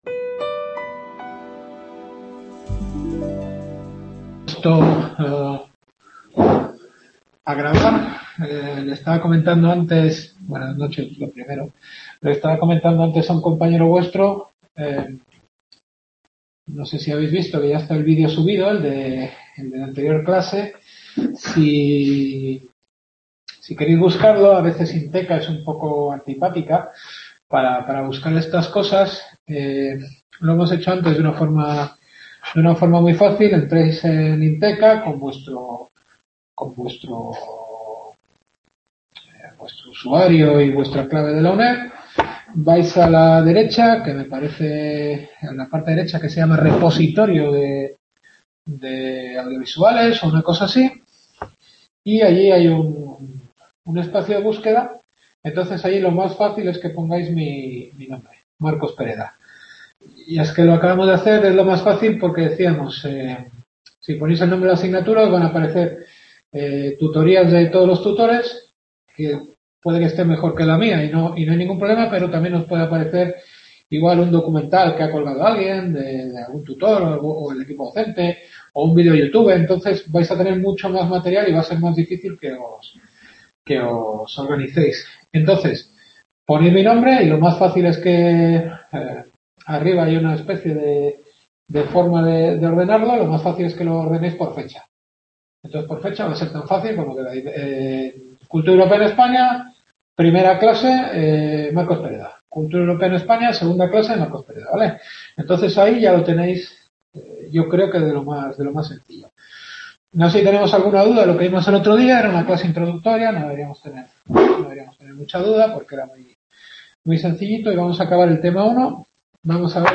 Cultura Europea en España. Segunda clase.